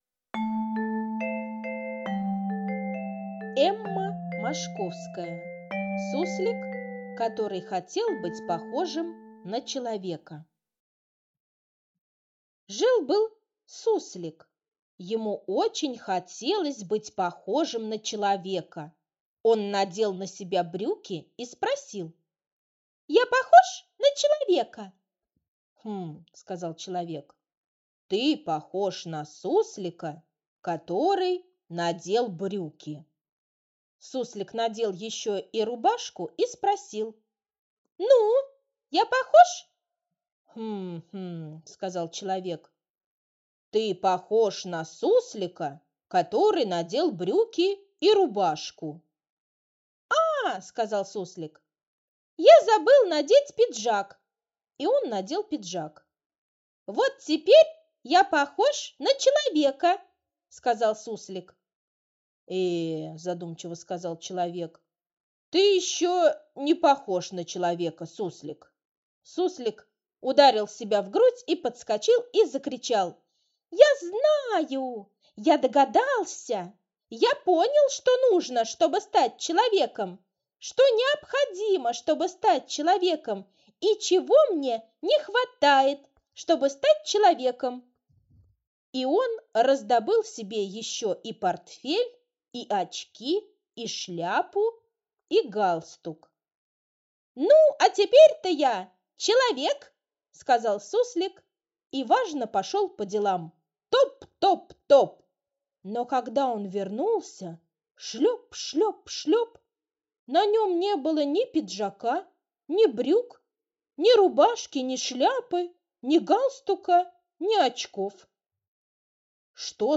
Аудиосказка «Суслик, который хотел быть похожим на человека»